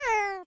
animalia_cat_hurt.ogg